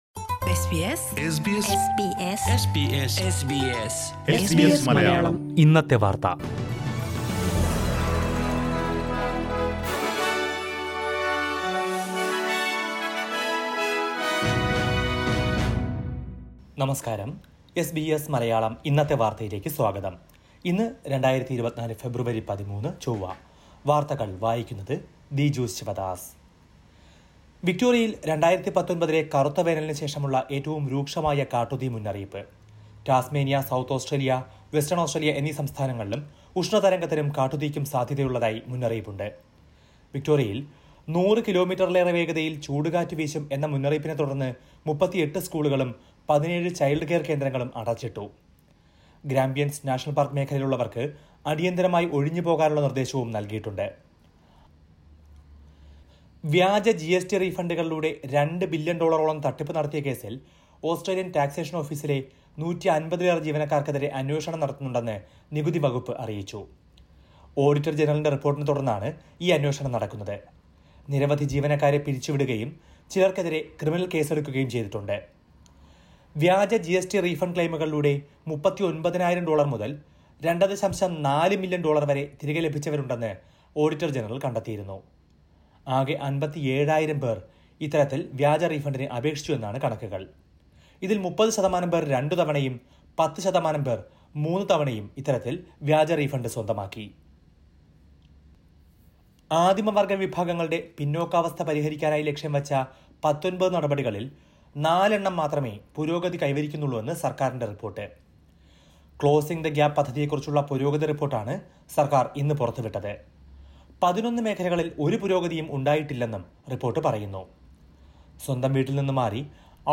2024 ഫെബ്രുവരി 13ലെ ഓസ്‌ട്രേലിയയിലെ ഏറ്റവും പ്രധാന വാര്‍ത്തകള്‍ കേള്‍ക്കാം...